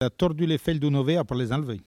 Langue Maraîchin
Patois